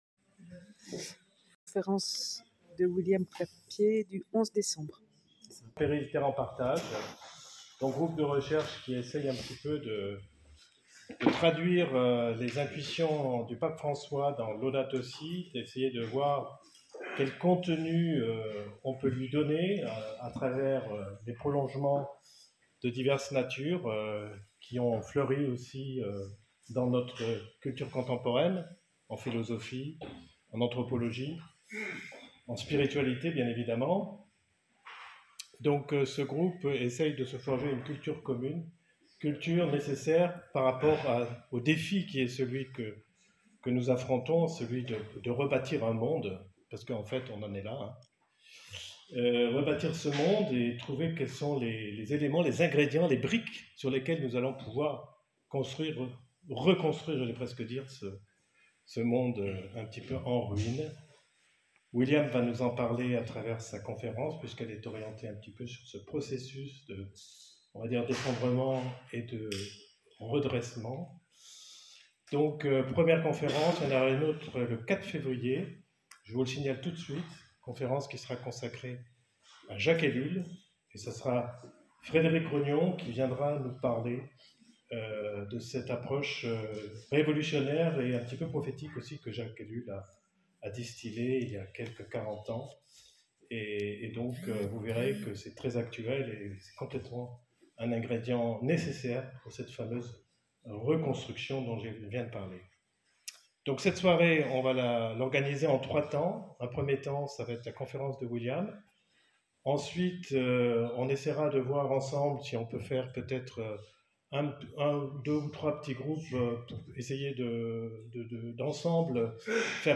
Conférence – L’espérance à l’épreuve du chaos mondial, un appel au sursaut spirituel – Centre Théologique de Meylan-Grenoble